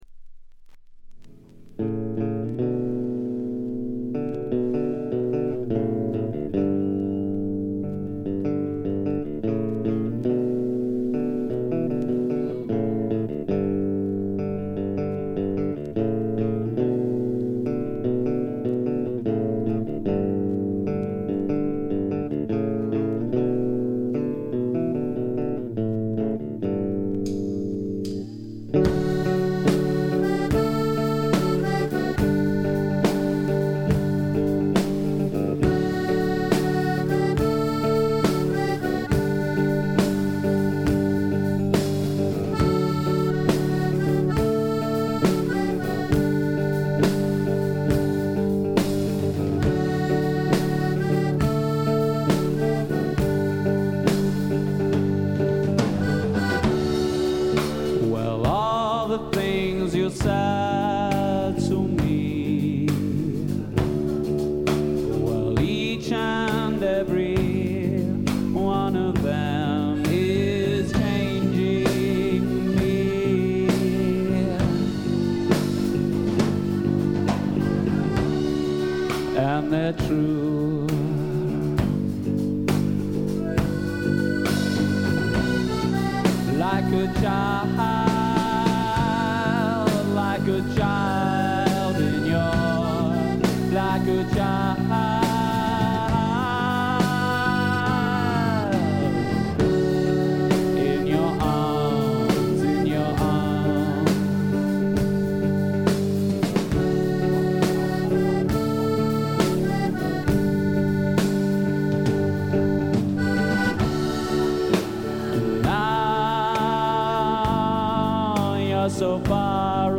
英国のバンドの小さなクラブでののライヴ盤です。
素敵なフォーク・ロックを聴かせてくれます。
全編に鳴り響くメロデオンのチープな音がいい感じですね。
試聴曲は現品からの取り込み音源です。
Recorded live at Moles Club, Bath: September 1988.